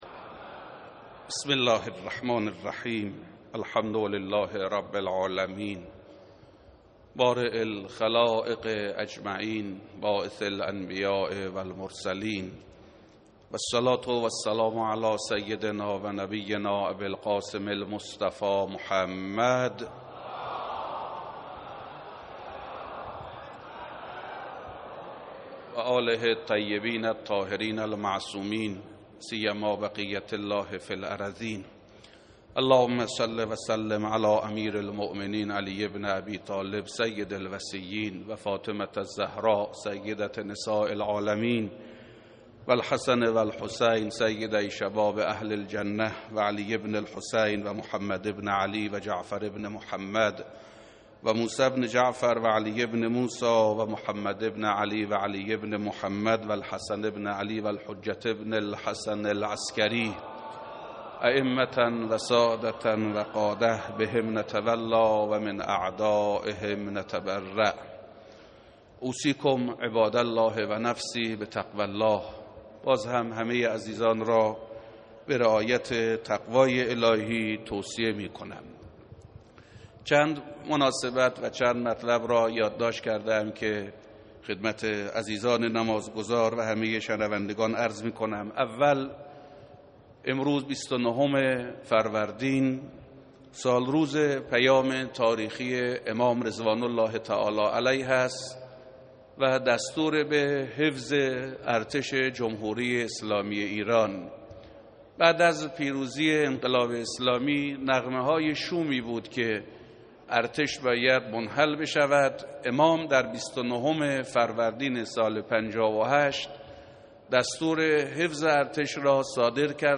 خطبه دوم